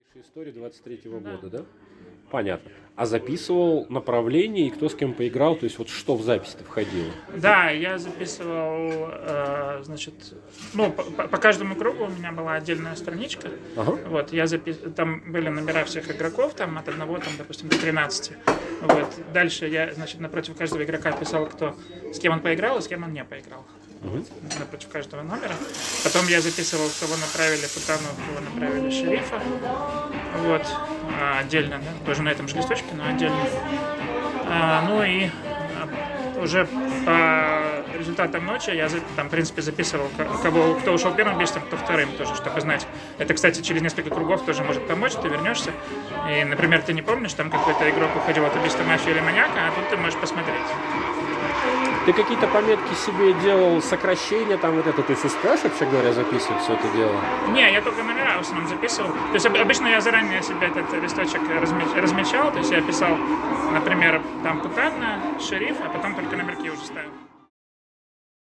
Фрагмент интервью:
tt_interview_take3.mp3